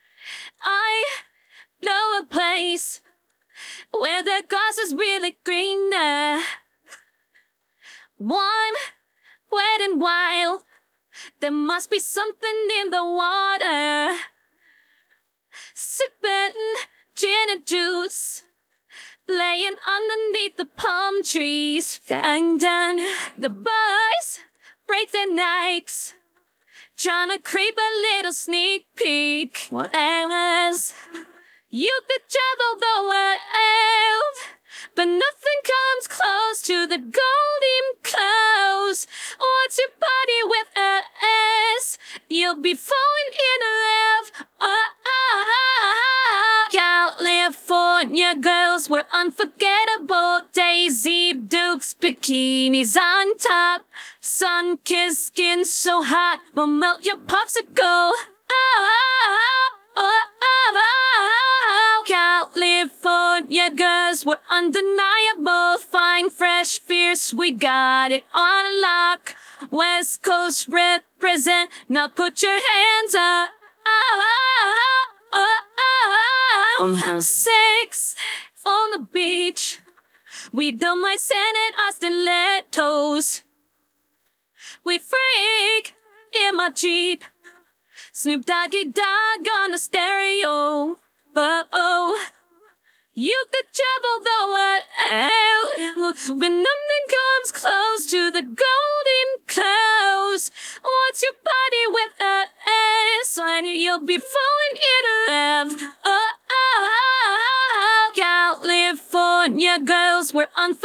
# 女声# 主播